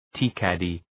Προφορά
{ti:’kædı}